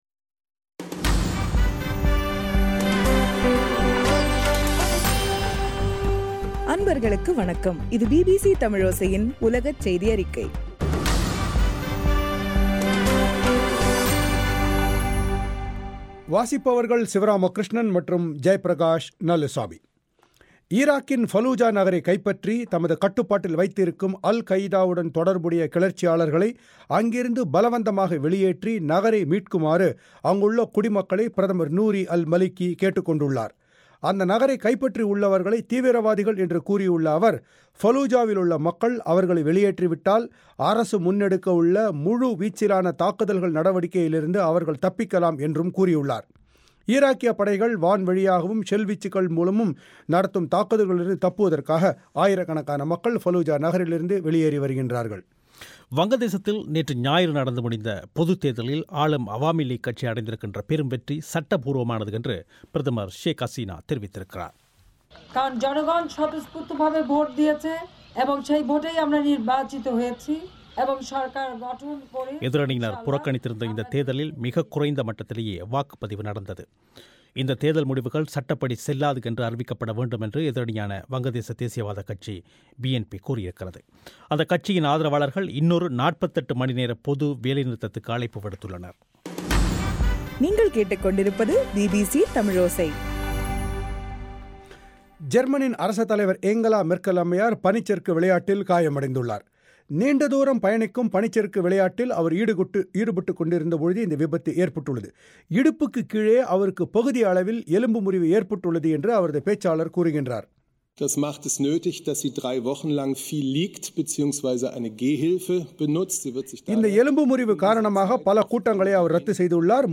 பிபிசி தமிழோசையின் உலகச் செய்தியறிக்கை- ஜனவரி 6